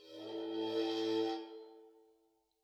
susCymb1-bow-2.wav